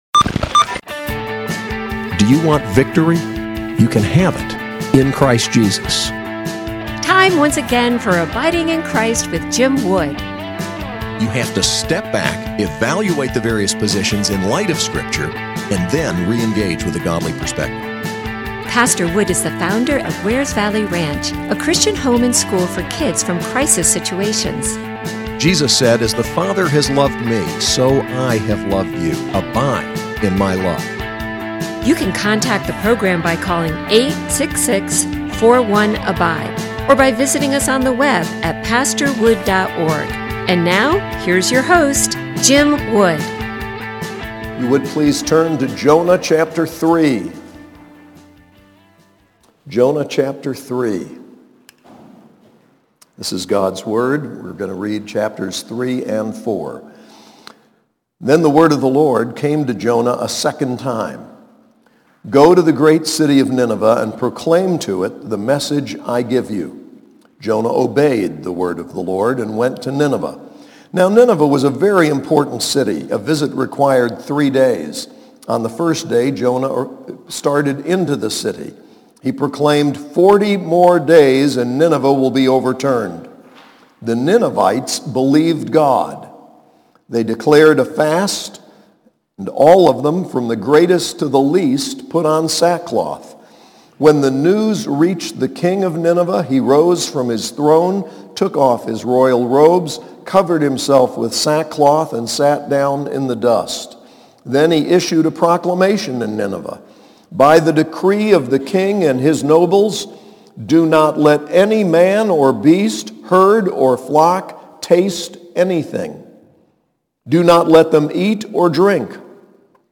SAS Chapel: Jonah 3-4